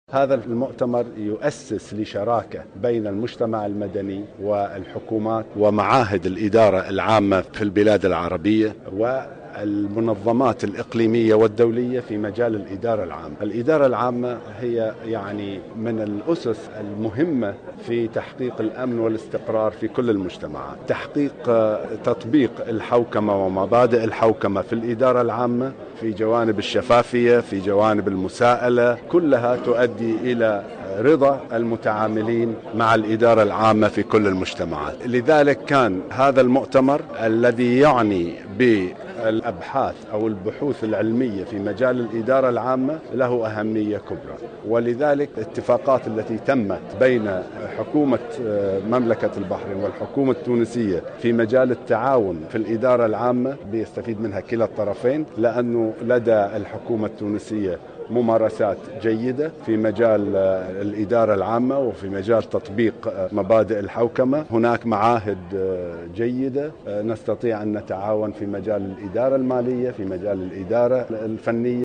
وأوضح وزير مجلس الوزراء في البحرين محمد ابراهيم المطوع في تصريح